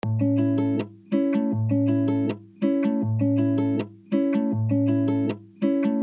دقت فرمایید که این پکیج گیتار، وی اس تی و یا ساز مجازی نمی باشد و تمام ریتم ها، آرپژ ها و موارد دیگر در استودیو با بالاترین کیفیت رکورد شده اند!
آرپژ شیش و هشت بندری لفت و رایت (بستکی)
6.8-bandari.mp3